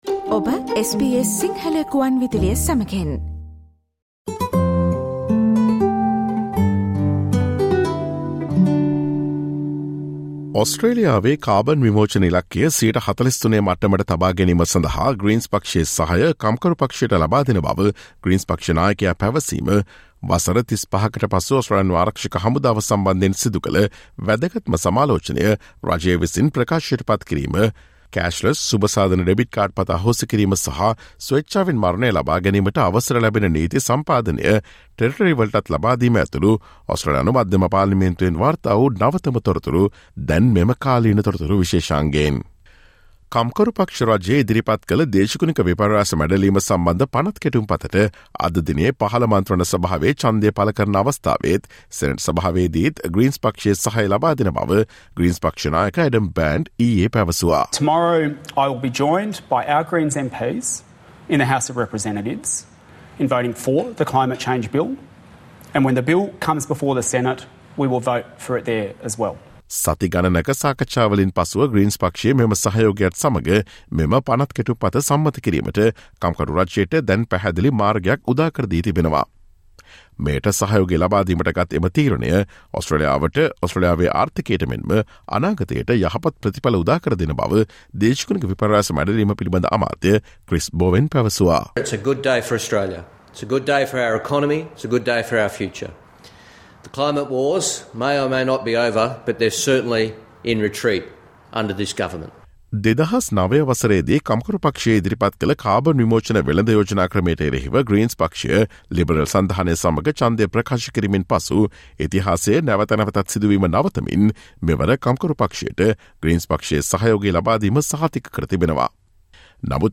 Listen to the SBS Sinhala Radio's current affairs feature broadcast on Thursday 04 August with information about what happened in the Australian Federal Parliament yesterday.